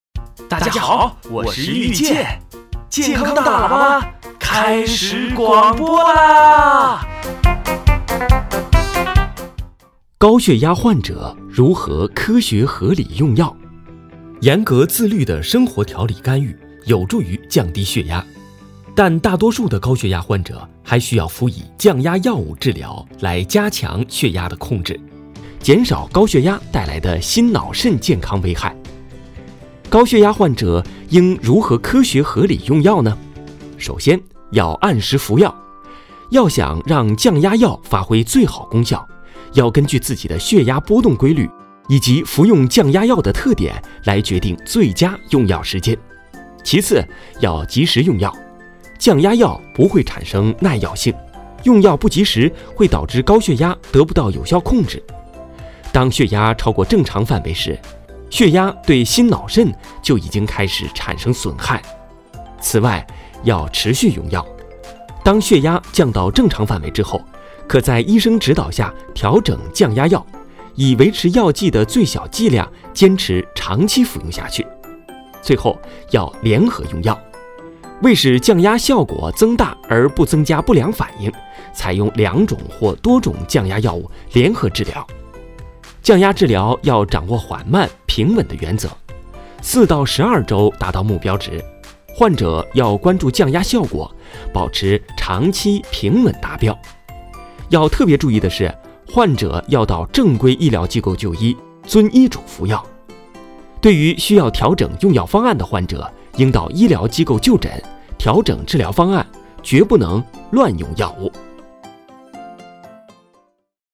大喇叭-高血压患者如何科学合理用药.mp3